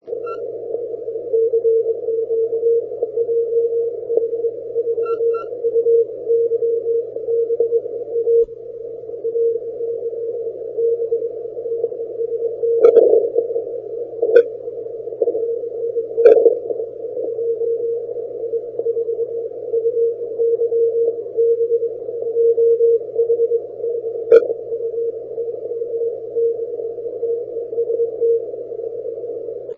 If possible use headphones to improve the copy....these recordings are mostly of difficult, lowband QSO's which are often just at the edge of readability.....some signals are very light, but  mostly all Q-5.....afterall...nobody works 80 or 160 without headphones...do they?